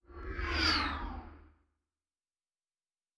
Distant Ship Pass By 2_1.wav